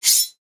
sword-unsheathe5.wav